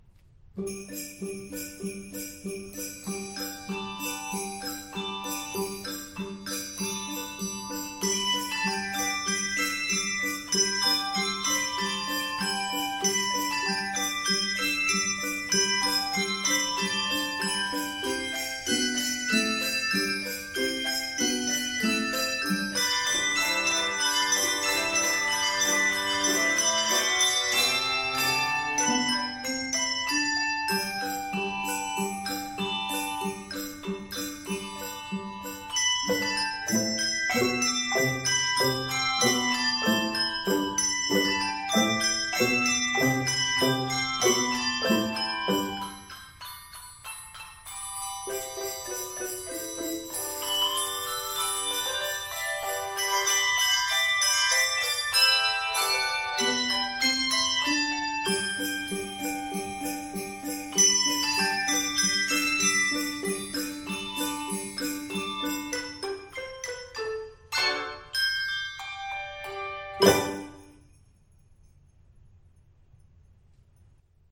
Percussion parts included. 28 measures. Key of G Major.